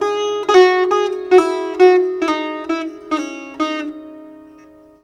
SITAR LINE23.wav